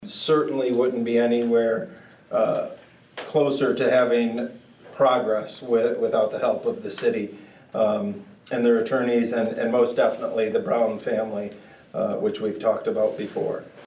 It was revealed this summer that the operation of the pool was in the red by over $300,000 per year. Numerous meetings were held to find a solution and Superintendent Paul Flynn said it was a team effort.